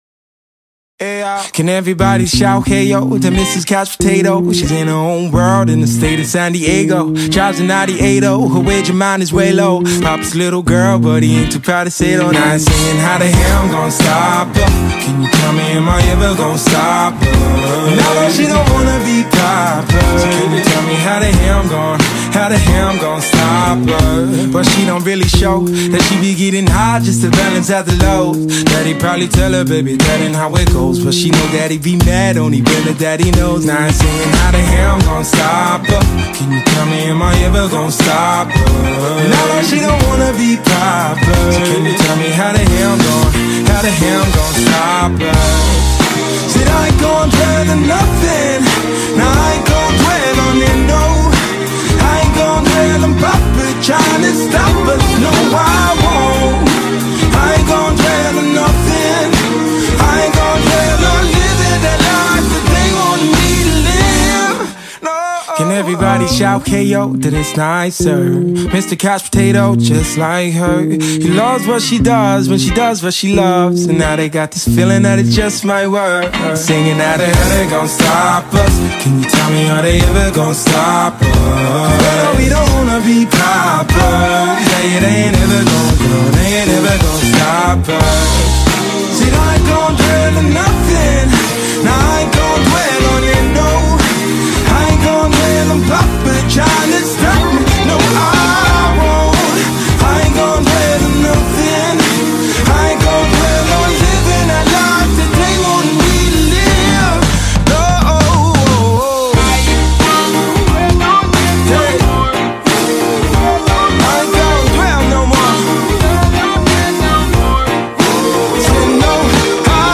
BPM45-90